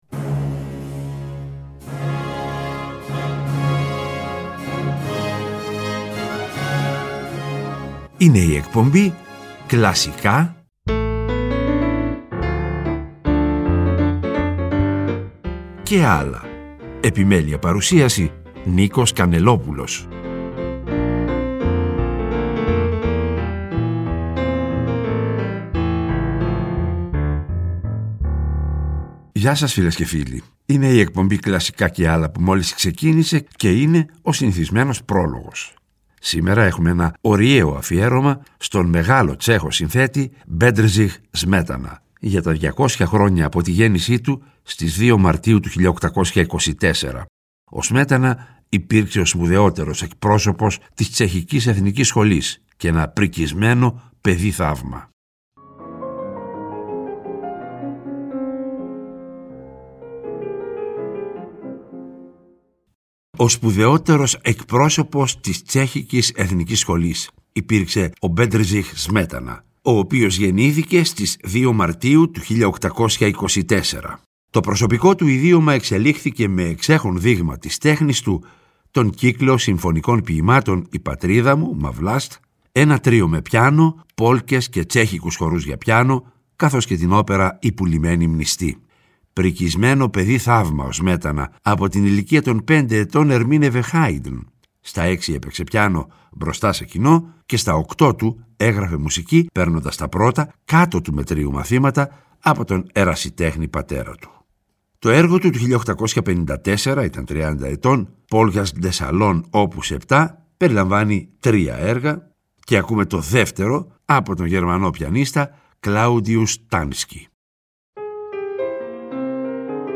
Ωριαίο αφιέρωμα στον μεγάλο Τσέχο συνθέτη Μπέντριχ Σμέτανα, για τα 200 χρόνια από τη γέννησή του στις 02.03.1824. Υπήρξε ο σπουδαιότερος εκπρόσωπος της τσεχικής εθνικής σχολής, προικισμένο παιδί-θαύμα. Μεταξύ των έργων που θα ακουστούν, με χρονολογική σειρά, είναι: Polkas de salon op.7 (αρ.2/3), Φαντασία πάνω σε τσεχικά λαϊκά τραγούδια, Πουλημένη μνηστή (πόλκα & χορός), Οι Βρανδεμβούργειοι στη Βοημία, Ο Μολδάβας, Όνειρα (αρ.3/6), Τσέχικοι χοροί (αρ.7/10).
ΤΡΙΤΟ ΠΡΟΓΡΑΜΜΑ